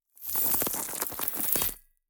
Free Frost Mage - SFX
Crystallize_06.wav